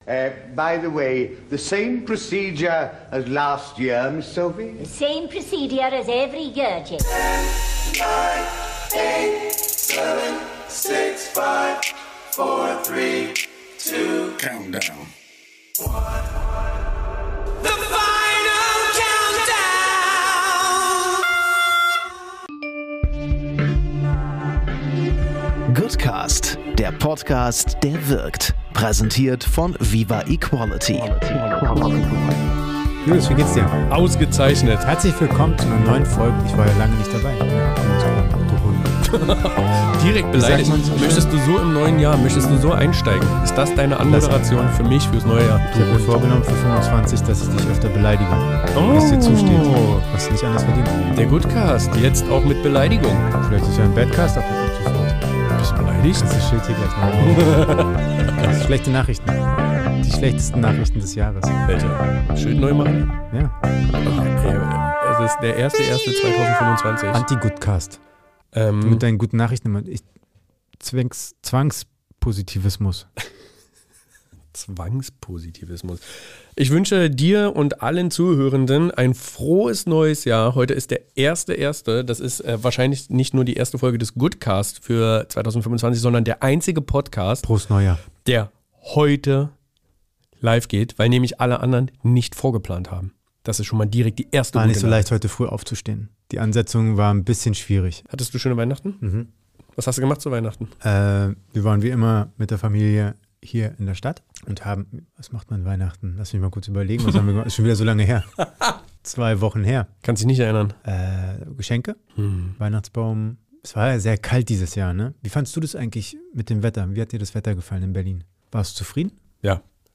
Diese Folge ist wahrlich ein chaotischer Podcast-Sturm der Kategorie „extrem geil“.